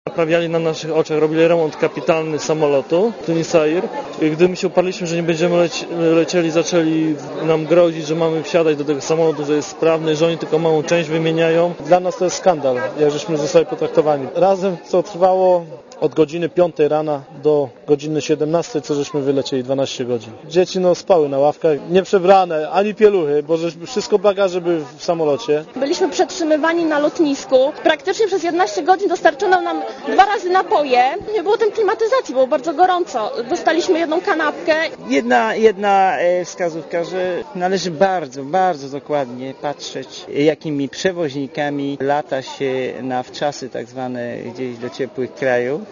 * Posłuchaj, co mówili turyści po powrocie do Polski*